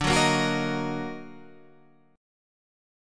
Ebm/D chord